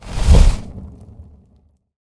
meleeattack-swoosh-magicaleffect-group01-fire-01.ogg